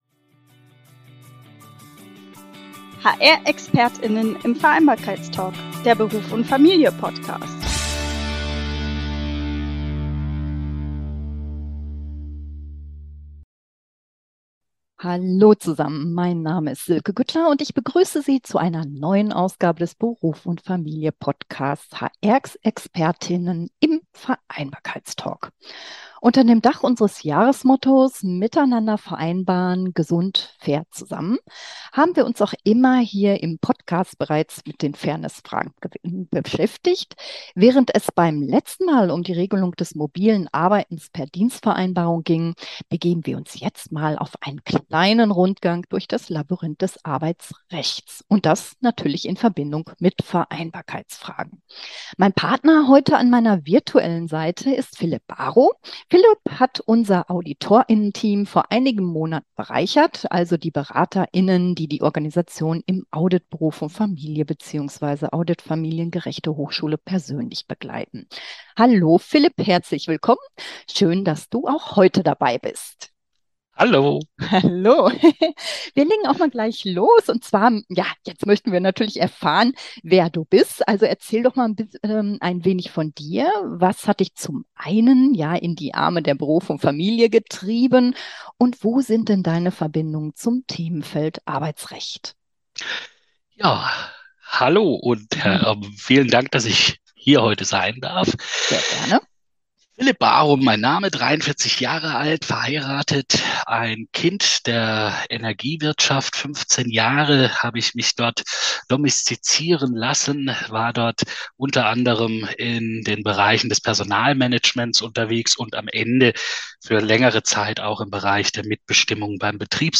Konkreter wird es bei der Betrachtung des bereits mehrfach modernisierten Teilzeit-Befristungsgesetzes, der Möglichkeit der Ausbildung in Teilzeit sowie der Fortsetzung der Altersteilzeit. Außerdem beschäftigt uns in dem Gespräch, ob ein – teilweise geforderter – Diskriminierungsschutz im Allgemeinen Gleichbehandlungsgesetz als Erweiterung des Arbeitsrechts sinnvoll und praktikabel wäre.